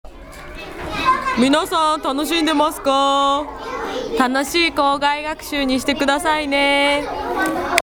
児童が企画したバスレクの先生声あてクイズ　　ご家庭でもお子様とチャレンジしてみてください。